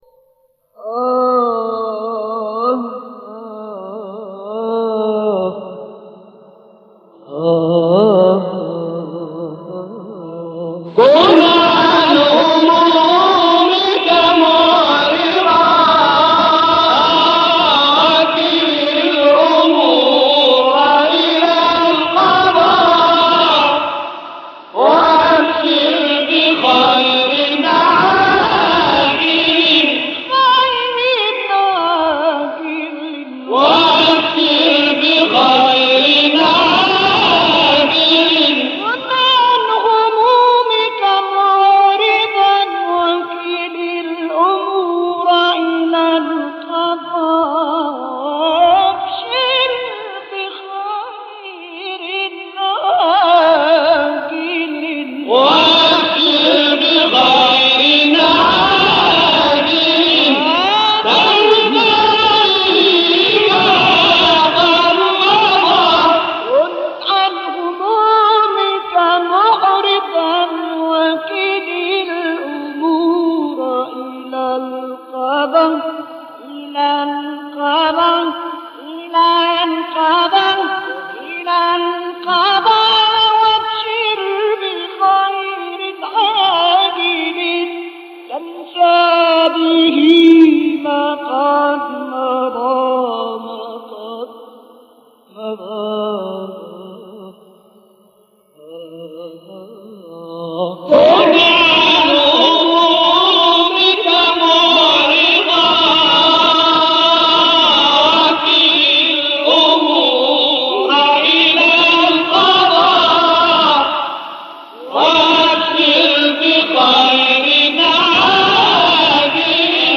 مقام العجم (تواشیح ) كن عن همومك - لحفظ الملف في مجلد خاص اضغط بالزر الأيمن هنا ثم اختر (حفظ الهدف باسم - Save Target As) واختر المكان المناسب